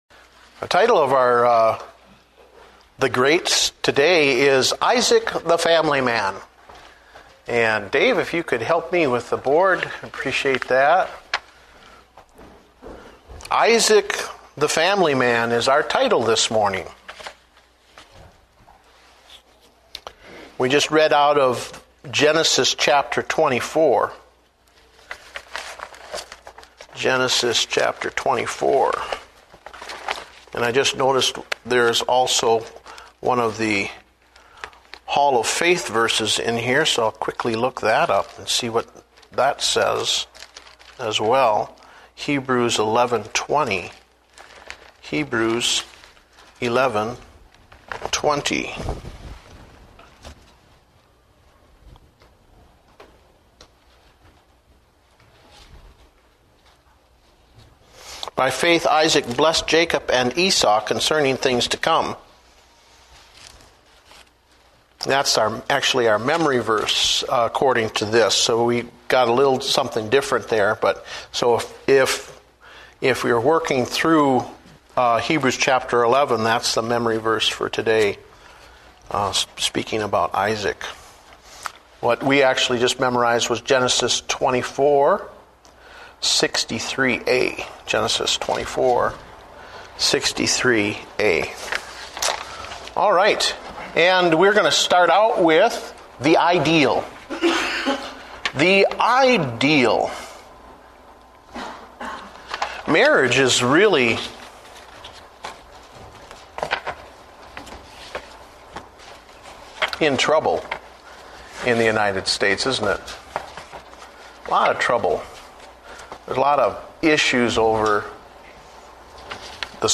Date: March 8, 2009 (Adult Sunday School)